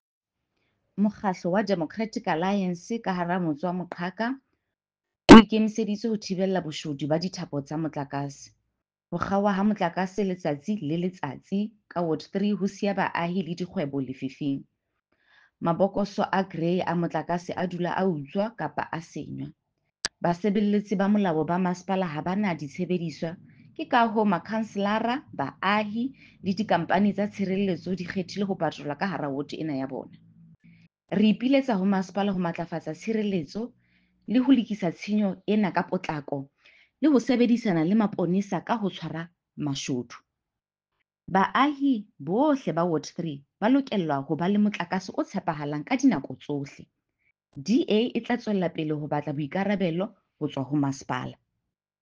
Sesotho soundbite by Cllr Mbali Mnaba.